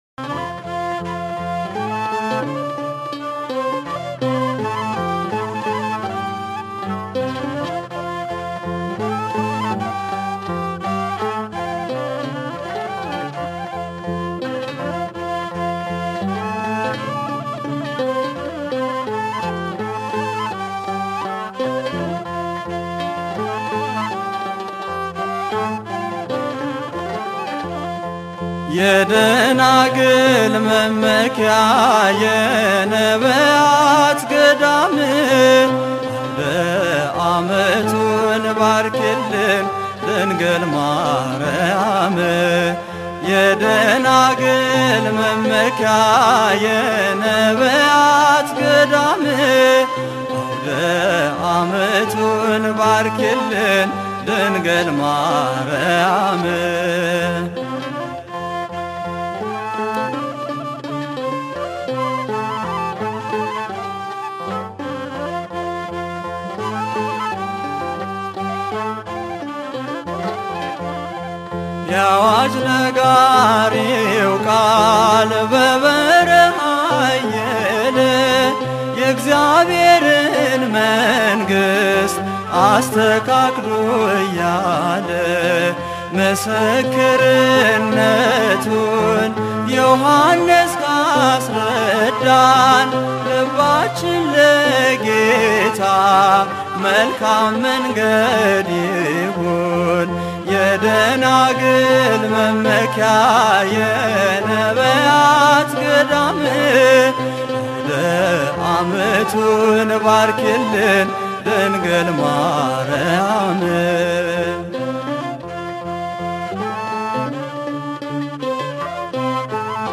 መዝሙር (ያዋጅ ነጋሪ ቃል) September 16, 2018